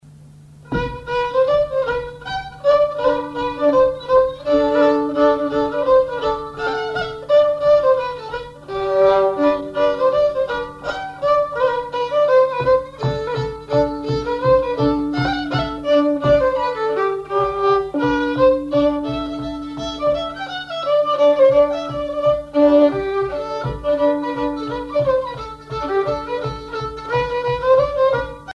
violoneux, violon
danse : java
Pièce musicale inédite